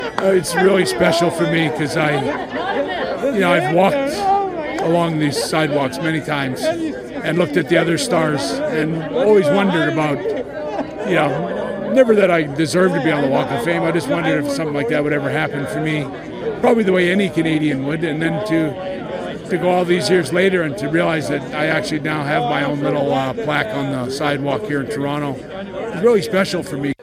Hart, who resides in Calgary, spoke about the Canadian walk of fame honor